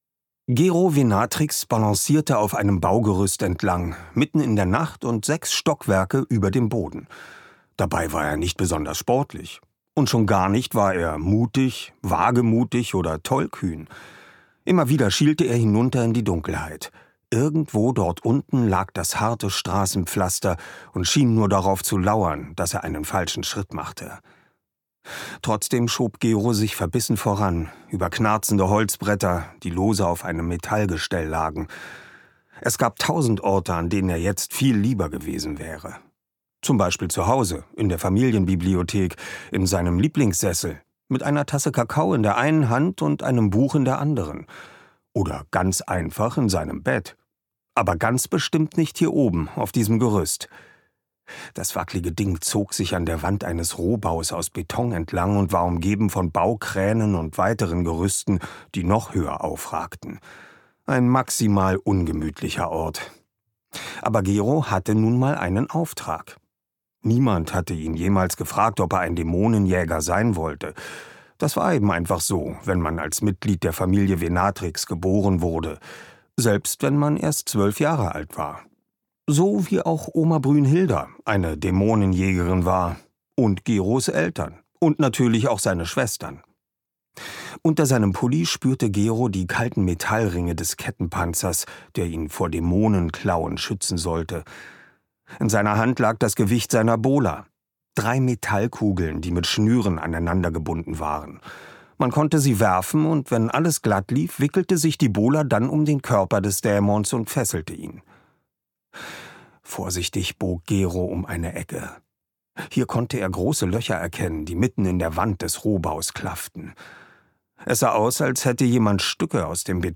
Details zum Hörbuch
Sprecher Oliver Rohrbeck